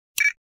switch.wav